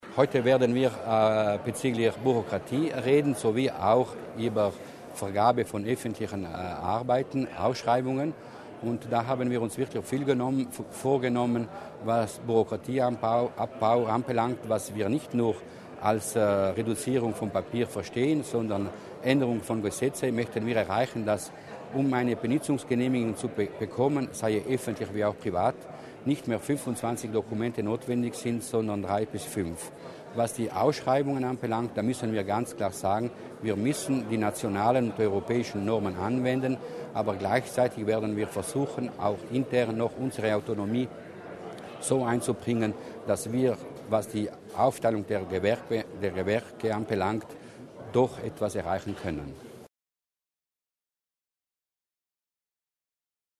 Bürokratieabbau und Neuerungen im Bautengesetz: Eine gut besuchte Tagung